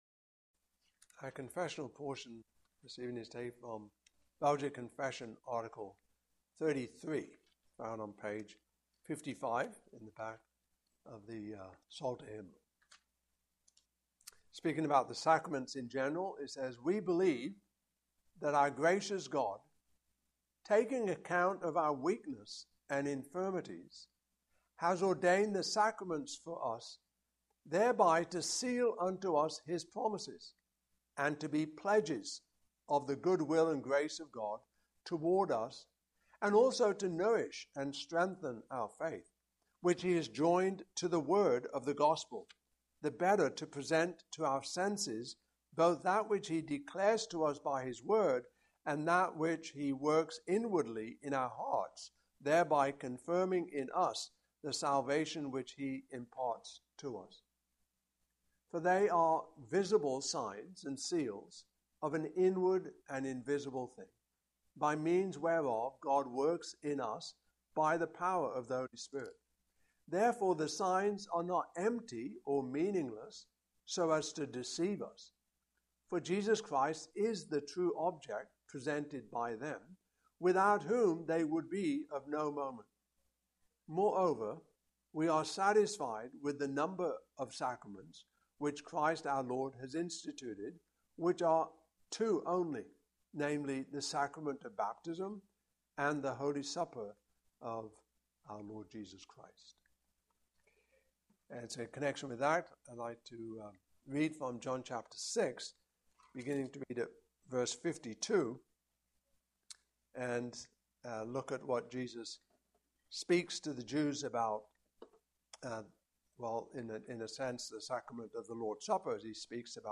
John 6:52-71 Service Type: Evening Service « By Scripture Alone Salvation by Grace